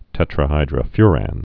(tĕtrə-hīdrə-fyrăn, -fy-răn)